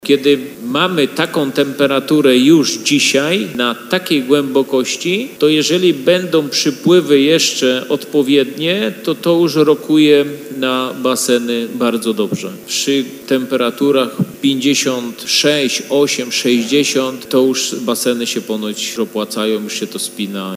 Podczas dzisiejszej sesji Rady Gminy Jasienica ze strony radnych padło pytanie na jakim etapie jest odwiert w Grodźcu, który ma wykazać czy w miejscowości tej są złoża wód geotermalnych i jakie mają właściwości.